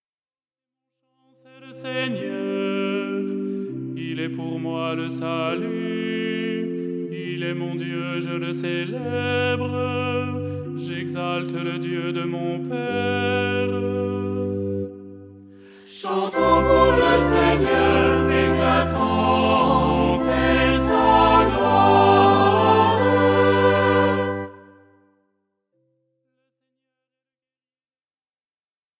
style simple et chantant